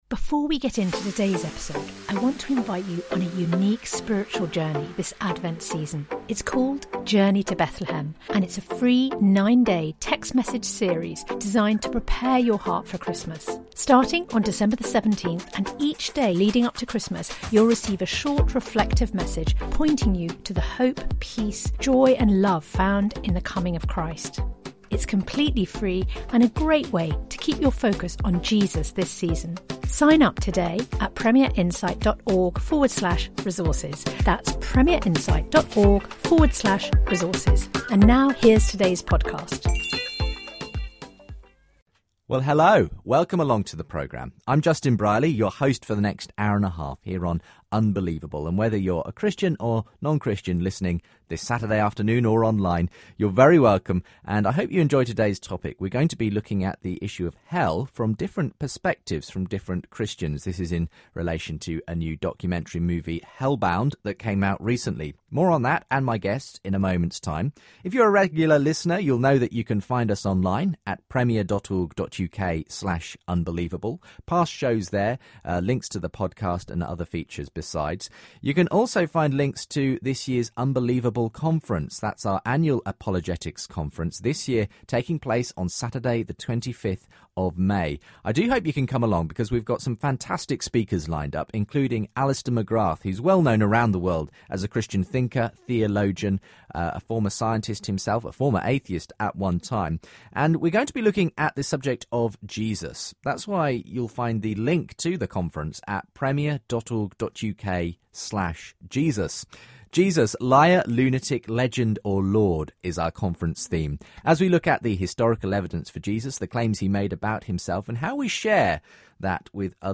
Hellbound the movie - debating three views on hell - Unbelievable?